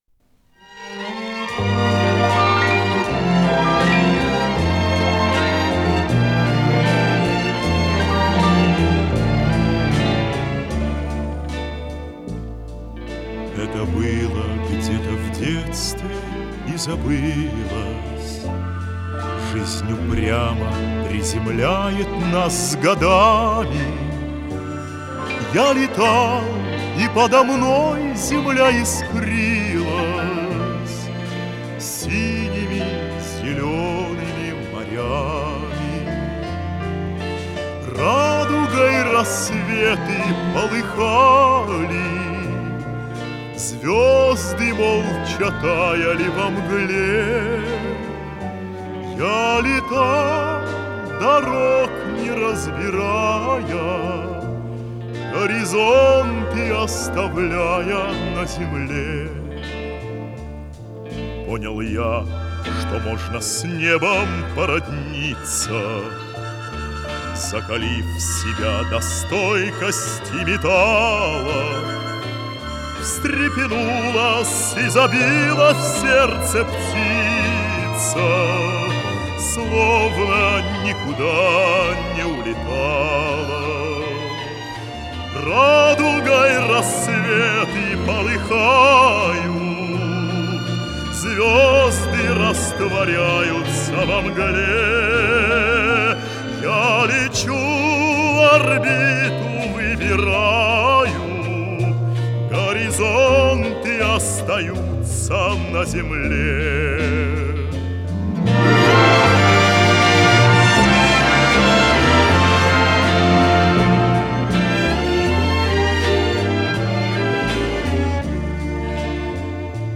с профессиональной магнитной ленты
баритон
ВариантДубль моно